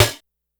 Snare_23.wav